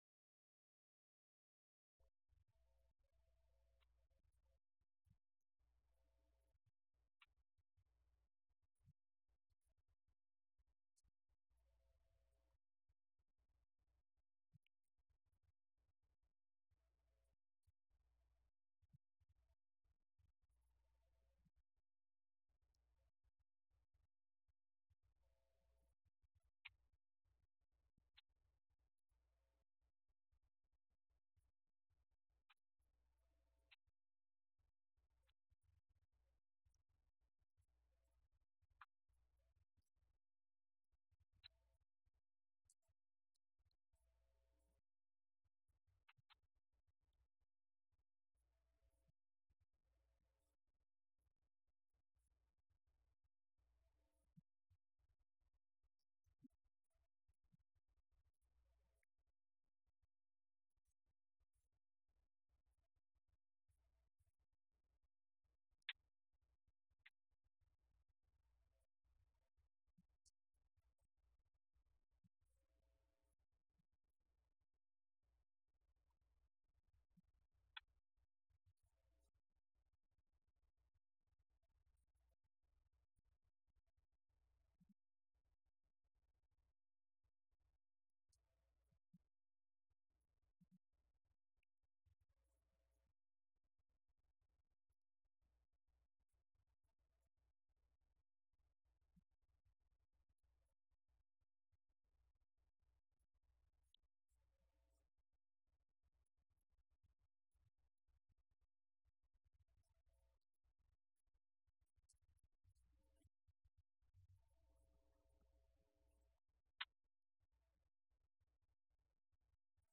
Event: 17th Annual Schertz Lectures
lecture